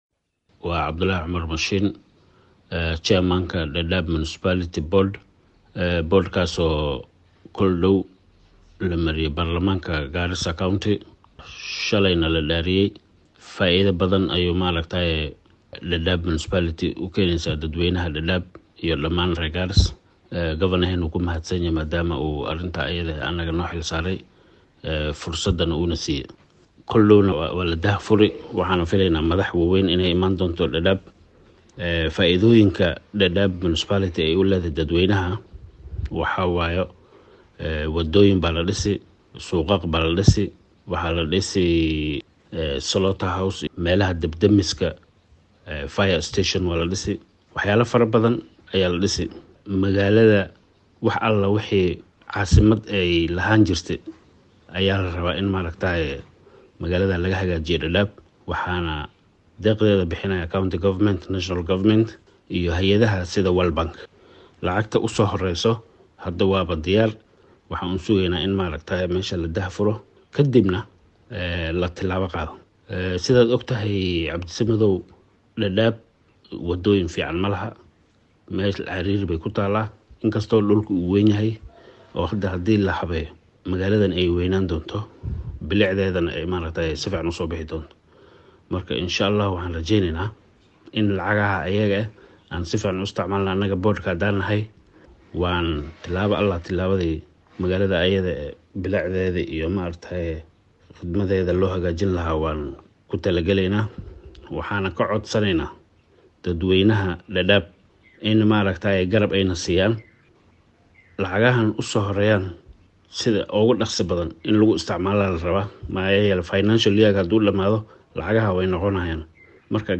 Guddoomiyaha cusub ee maamulka hoose ee magaalada Dadaab ee ismaamulka Garissa Cabdullahi Cumar Mashiin, qaar ka mid ah xubnaha guddiga iyo wasiirka arrimaha dhulalka iyo qorsheynta magaalooyinka ee dowlad deegaanka Garissa Maxamed Xuseen ayaa ka hadlay munaasabad lagu dhaariyey xubnaha guddiga. Guddoomiyaha ayaa dadweynaha ku nool Dadaab ugu baaqay inay guddiga garab ku siiyaan sidii loo wanaajin lahaa bilicda magaalada wuxuuna xusay in qorshaha maamul dagmeedka Dadaab uu yahay mid si wayn looga faa’idi doono.